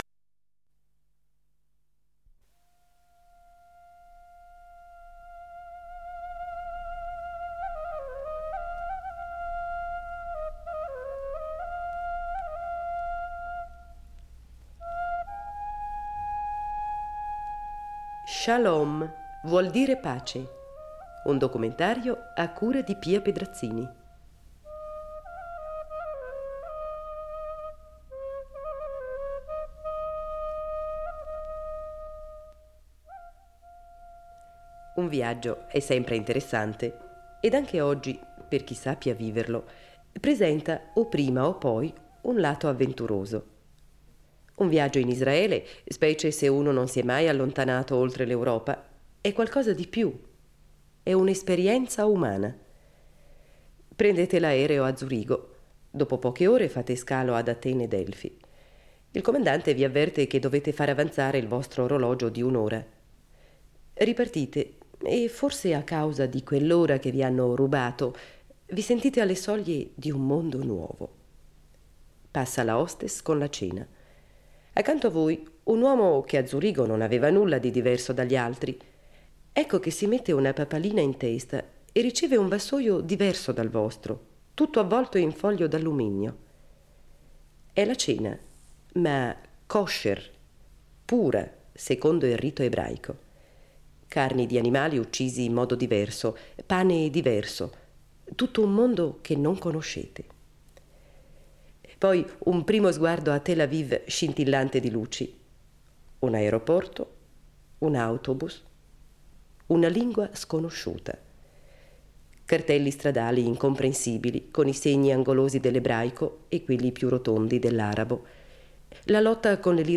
Tra coltivazioni e kibbutz, tra il mare e l’entroterra, il viaggio attraversa Be’er Sheva, raggiunge il porto di Elat e risale lungo le rive del Mar Morto fino a Haifa. Nel corso del reportage emergono interrogativi sul futuro economico di un Paese nato da meno di vent’anni, ancora alla ricerca di un equilibrio tra sviluppo e identità.